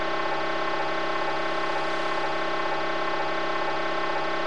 Each can be identified by the distinct sound of its data channel:
Ericsson EDACS |
edacs.wav